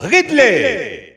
Announcer pronouncing Ridley in French.
Ridley_French_Announcer_SSBU.wav